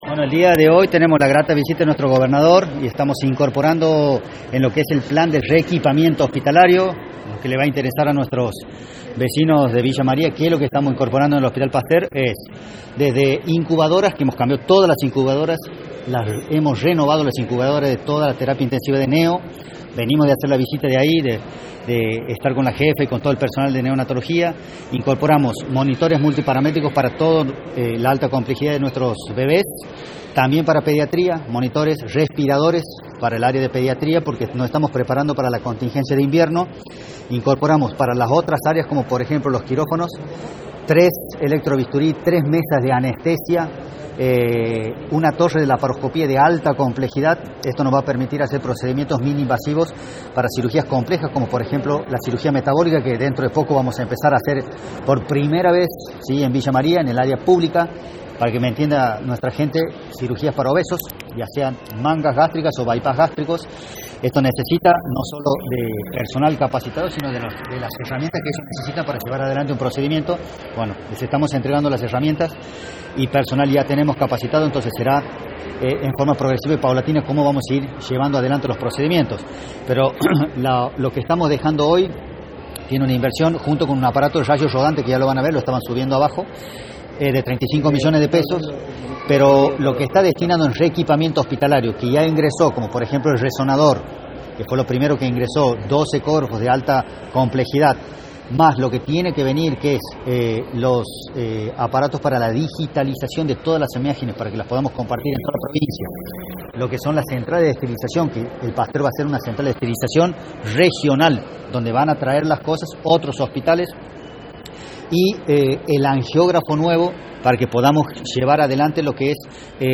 AUDIO- DIEGO CARDOZO, MINISTRO DE SALUD DE LA PROVINCIA